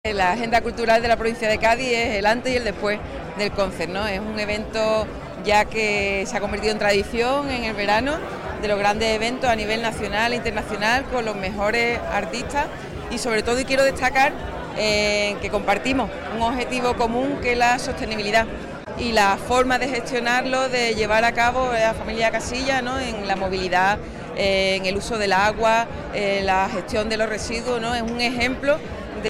La presidenta de la Diputación de Cádiz, Almudena Martínez, ha participado en Madrid en el acto de presentación de la octava edición del Concert Music Festival, el programa musical que cada verano acoge Chiclana de la Frontera en el entorno del poblado de Sancti Petri.
Presidenta-de-Diputacion-en-presentacion-Concert.mp3